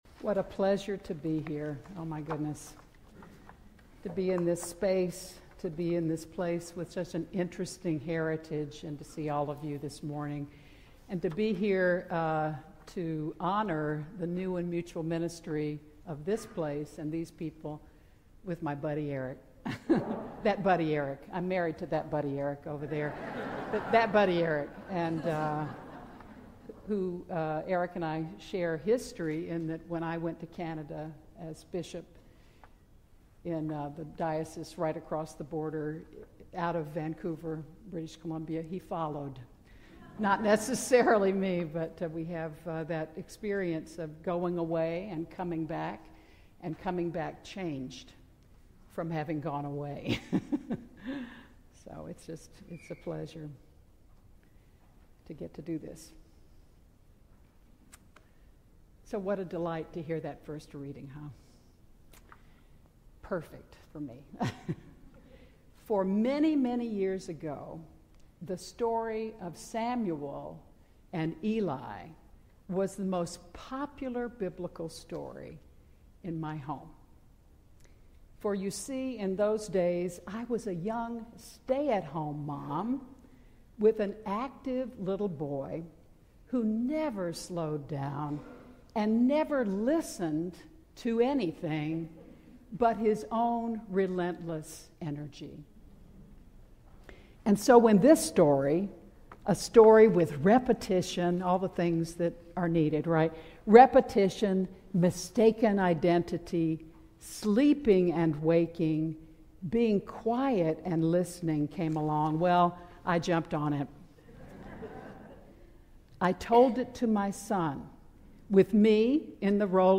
Sermons | Grace Episcopal Church
Celebration of New Ministry with Bishop Melissa Skelton
Guest Speaker